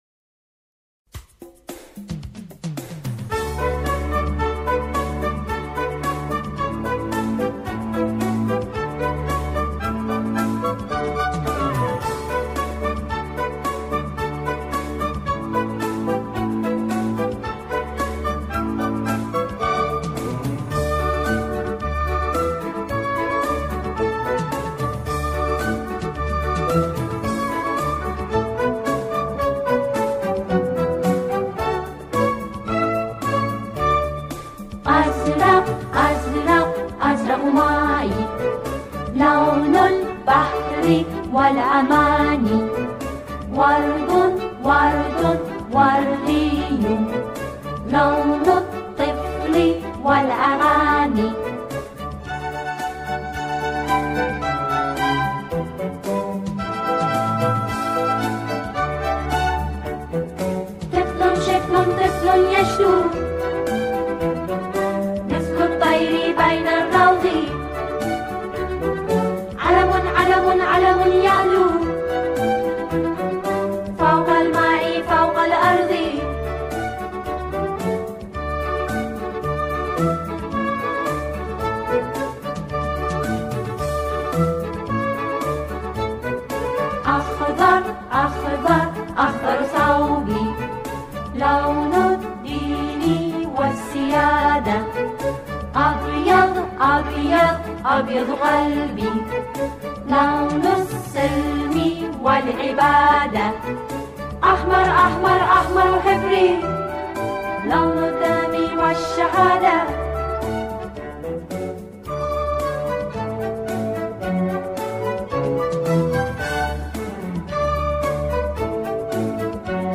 سرودهای انگیزشی